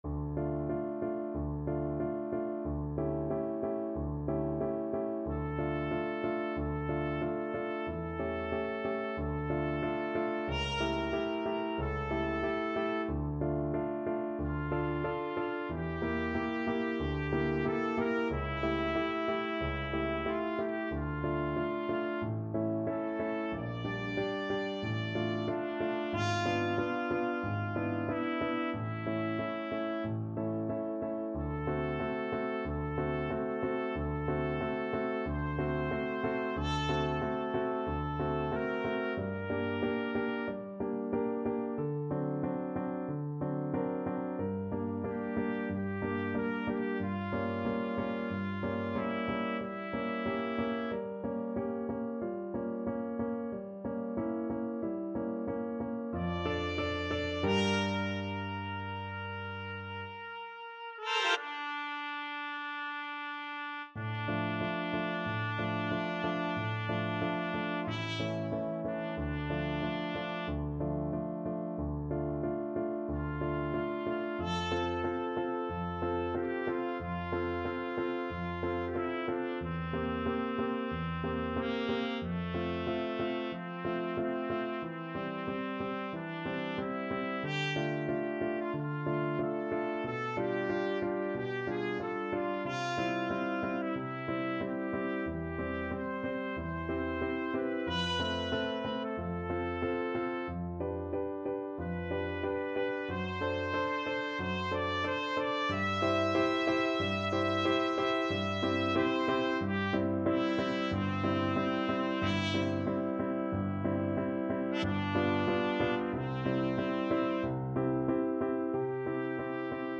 Trumpet version
Key: D minor (Sounding Pitch)
Time Signature: 4/4
Tempo Marking: Moderato = 46